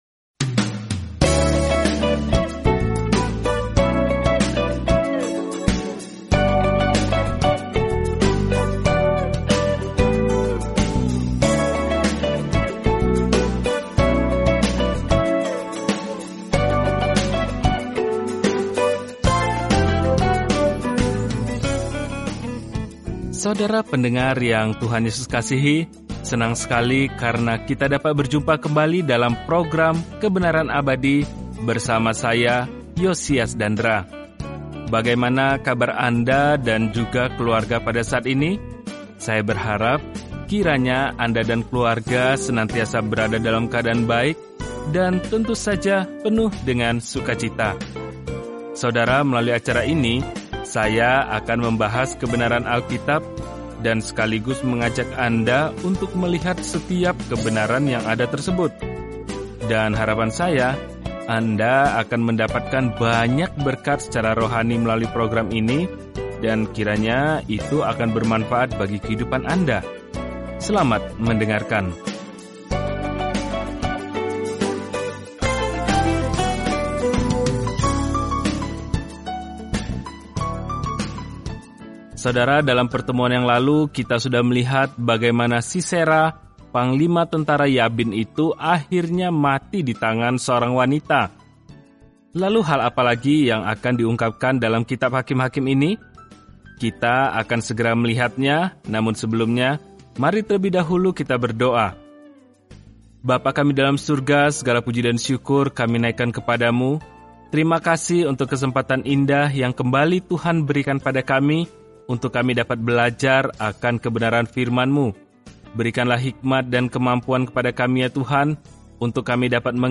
Firman Tuhan, Alkitab Hakim-hakim 5 Hari 2 Mulai Rencana ini Hari 4 Tentang Rencana ini Hakim-hakim mencatat kehidupan orang-orang yang terkadang berbelit-belit dan terbalik saat menjalani kehidupan baru di Israel. Perjalanan harian melalui Hakim-hakim saat Anda mendengarkan studi audio dan membaca ayat-ayat tertentu dari firman Tuhan.